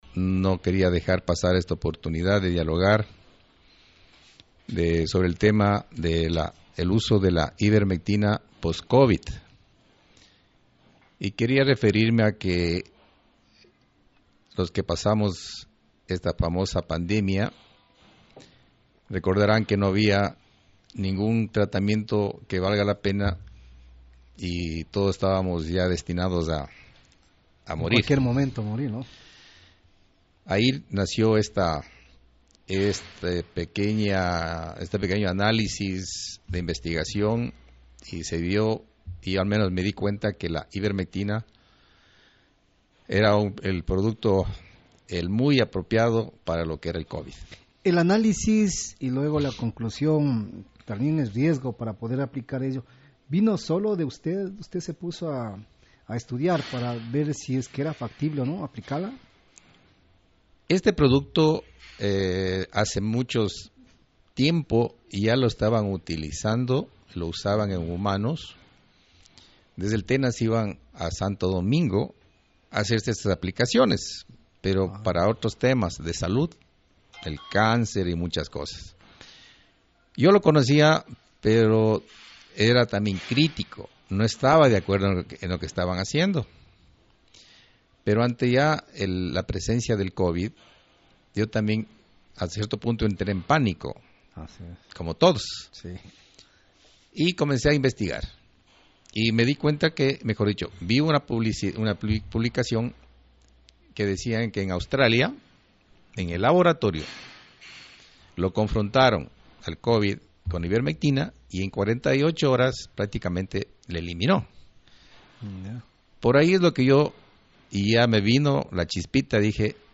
una entrevista en Nina Radio de Puyo